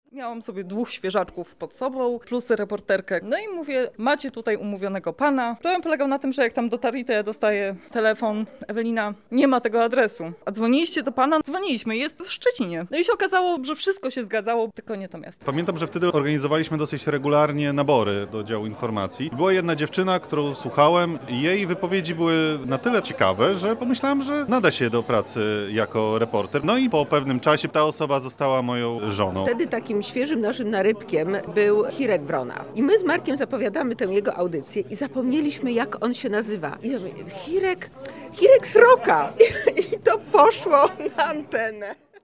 Przez trzy dekady mury Chatki Żaka niejedno słyszały, a częścią tych wspomnień podzielili się nasi byli redakcyjni koledzy i koleżanki.
sonda jubileusz